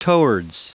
Prononciation du mot towards en anglais (fichier audio)